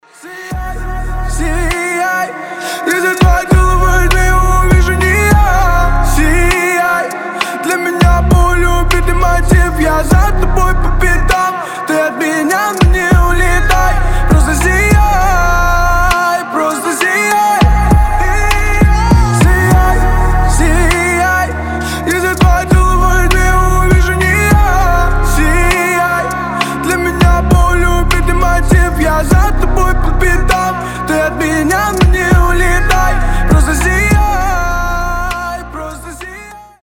• Качество: 320, Stereo
мужской голос
лирика
басы
медленные